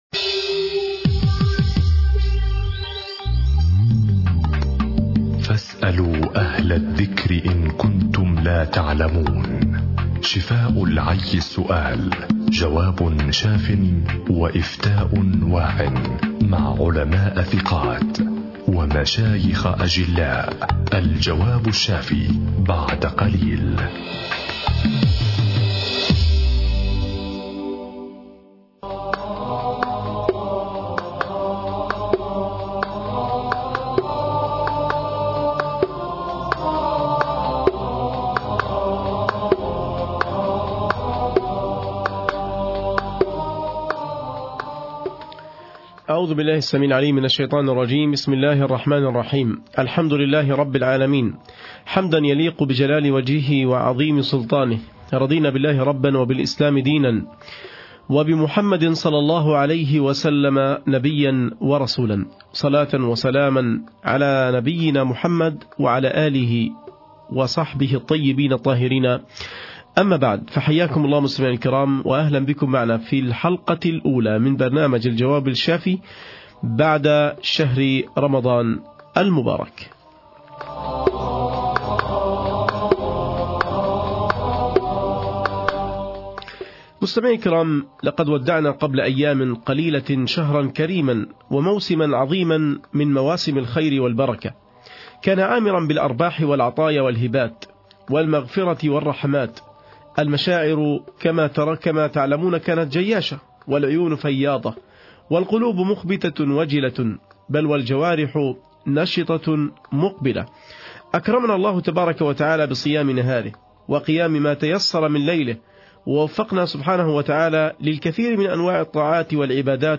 البرنامج الديني المباشر للقضايا الشرعية، يتحدث عن قضية شرعية، ويجيب على تساؤلات المستمعين.